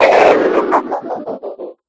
Edited the RA2 voice lines in Audacity to make cybernetic sounding versions I've been using for my Stormtrooper and Scorpion Cyborg units; figured I may as well share them here.